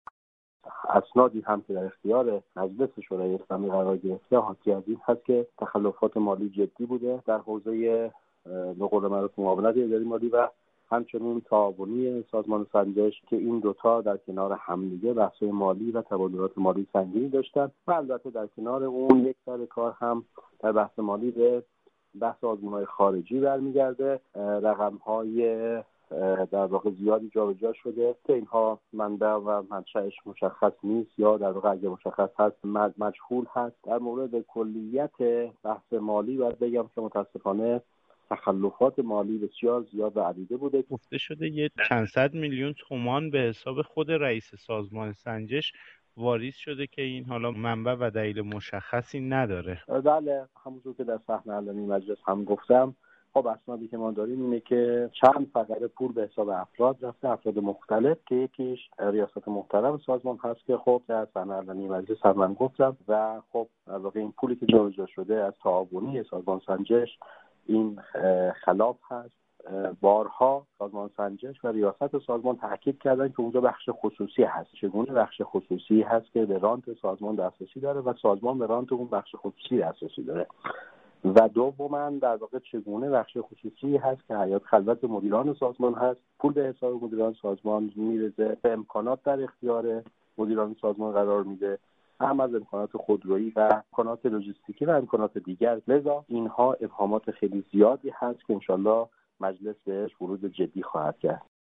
درضمن این مورد را هم اضافه كنیم كه تحقیق و تفحص از سازمان سنجش در مجلس شورای اسلامی، با موضوعات مالی و واریز میلیونی پول به حساب مدیران سازمان سنجش روبرو شده است، كه در گزارش صوتی همراه خبر، صحبتهای آقای نادری نماینده مجلس را می شنویم.